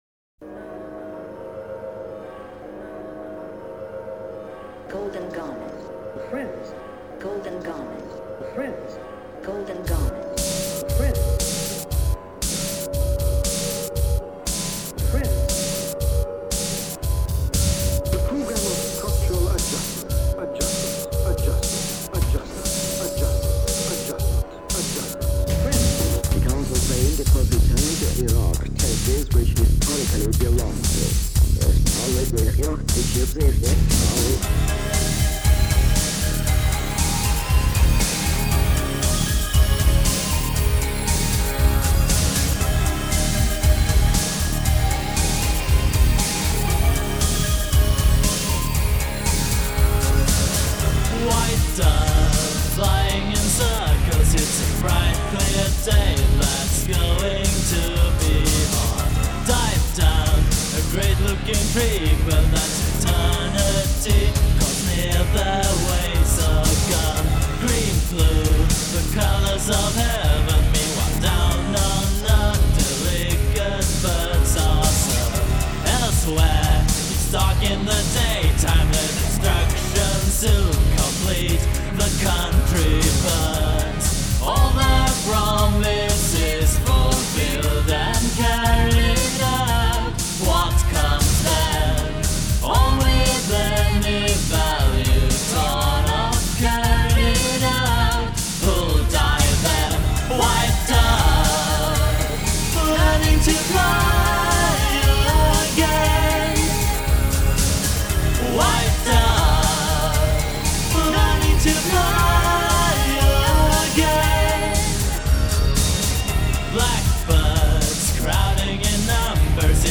Straight rock with very political lyrics.
Nice samples though.